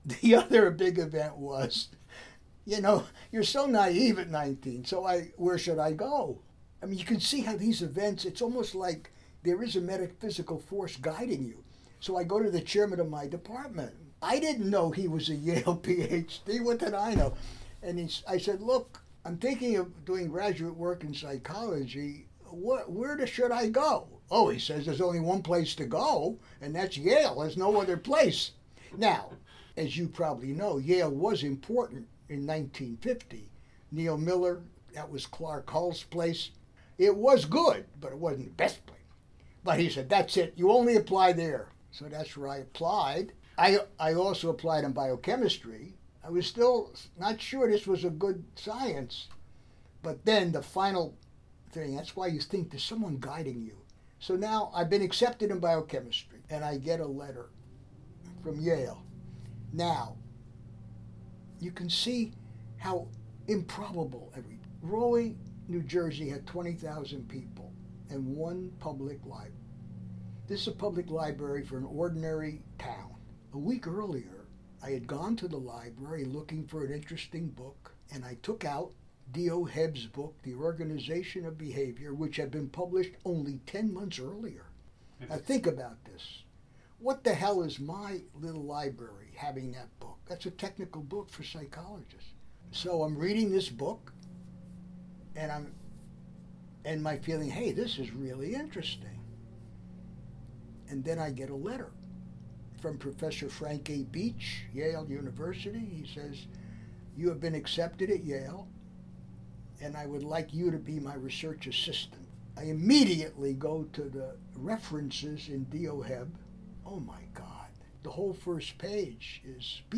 I hope you enjoy his animated retelling of those events that guided his decision to enter the Yale doctoral program of Psychology: